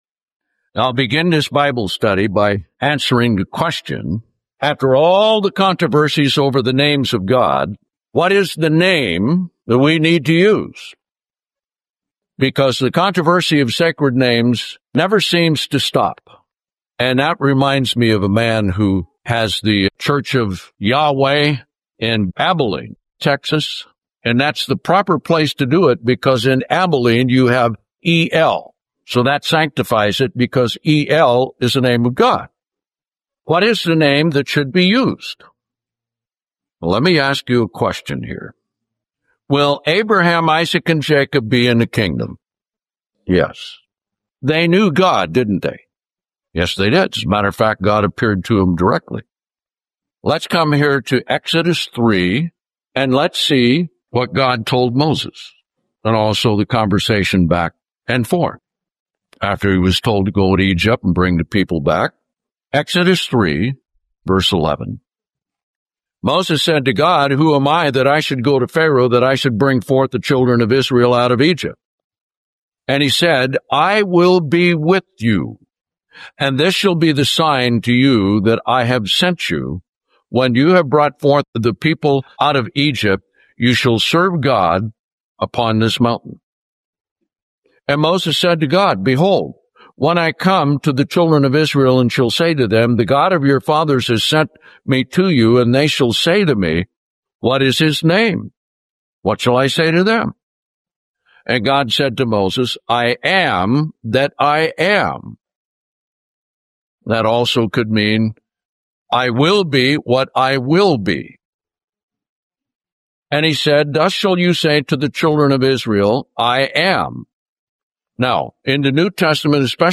Bible Study—New Testament Sacred Names